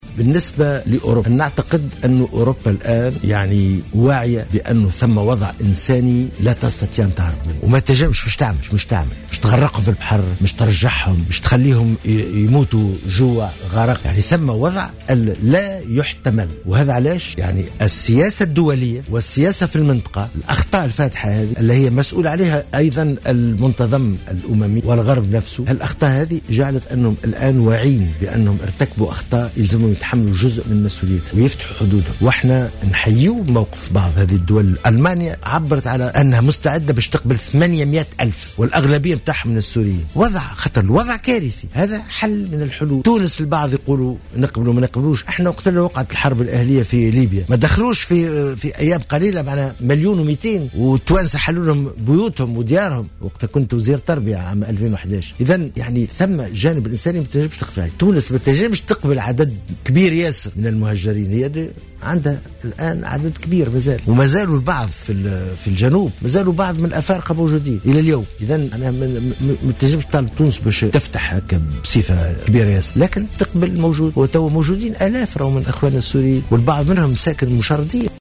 أكد وزير الشؤون الخارجية الطيب البكوش في حوار حصري لجوهرة أف أم أن اكتفاء تونس بإعادة قنصل الى سوريا جاء تقديرا للوضع الاقليمي الذي حتم التدرج في إعادة التمثيل القنصلي.